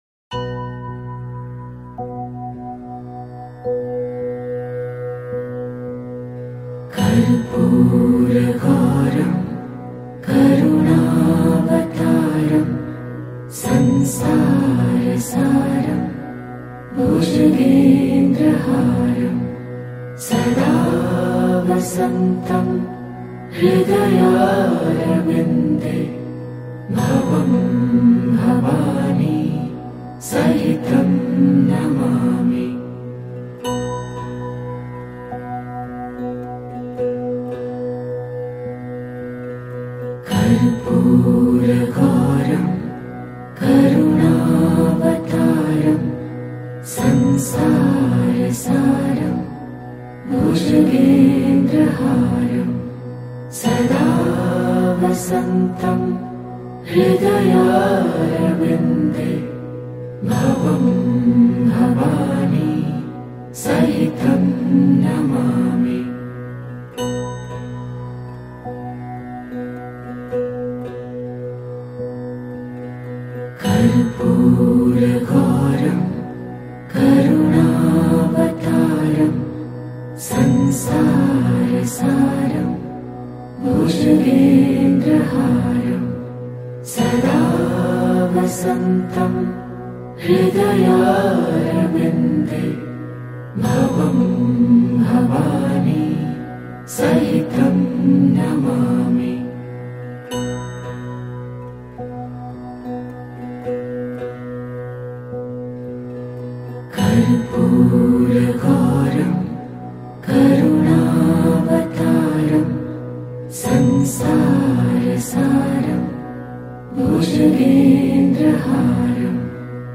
KARPUR-GAURAM-KARUNAVTARAM-CHANTING-108-Times-Peaceful-SHIVA-MANTRA-for-Inner-Peace-and-PROTECTION.mp3